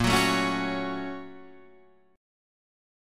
A#mM7#5 chord